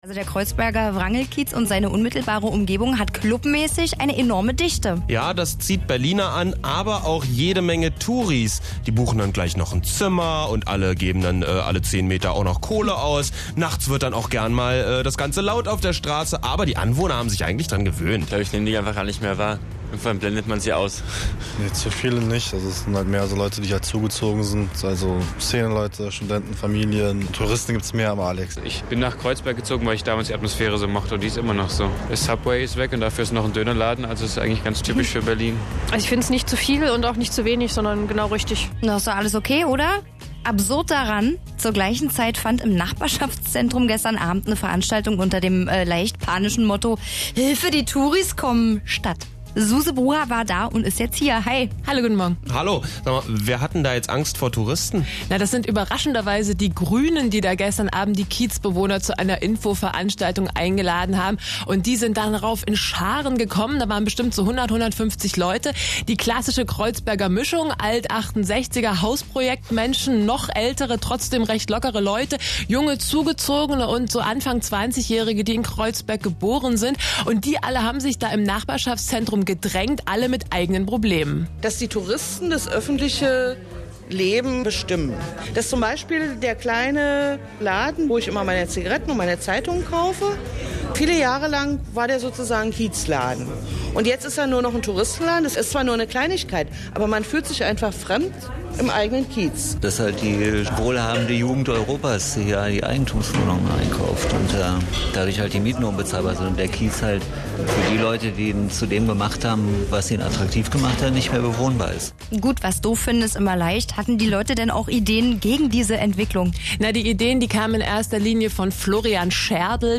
- Der Radiobeitrag zum Tourismus in Berlin Kreuzberg: